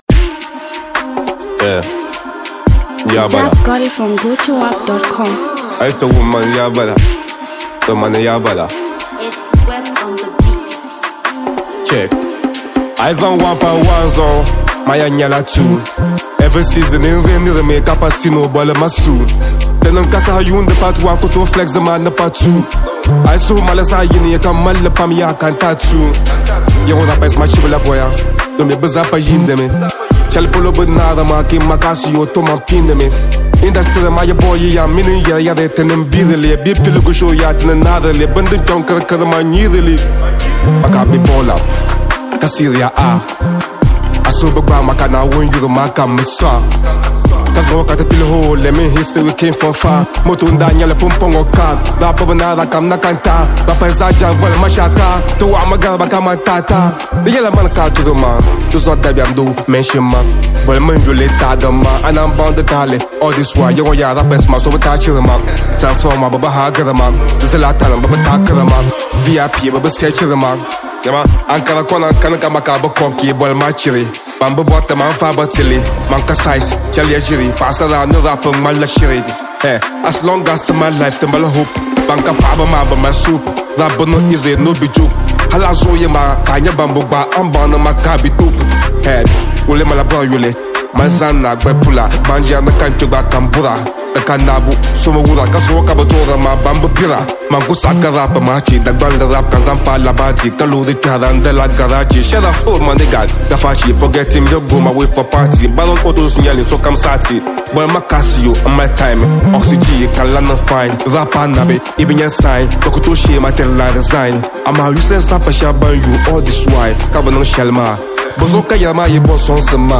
Ghanaian rap
powerful lyrical jam